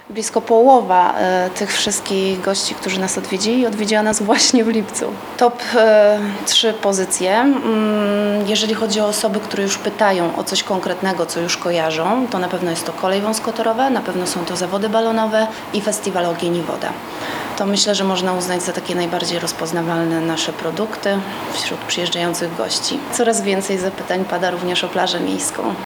Turyści, z którymi rozmawialiśmy, chwalą Ełk.